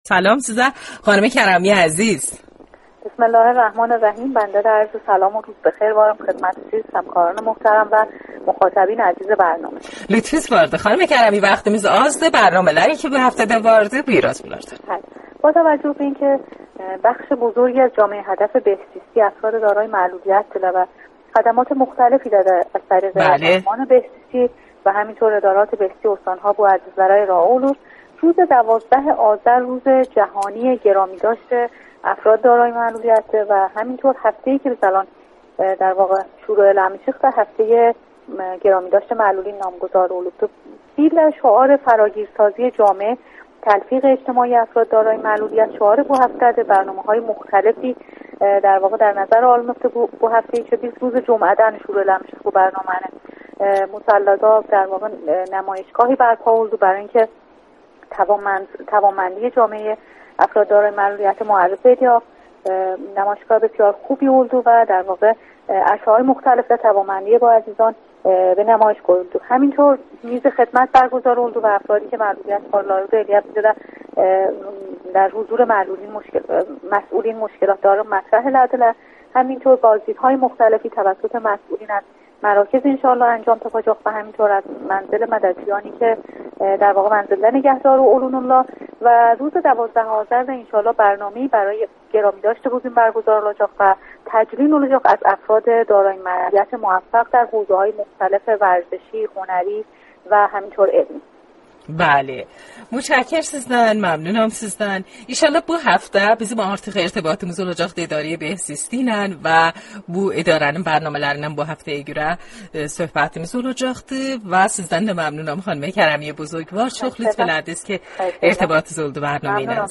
باهم بشنویم| مصاحبه رادیویی مدیرکل بهزیستی با برنامه یاشاییش از رادیو زنجان